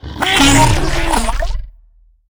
alien_language_01.ogg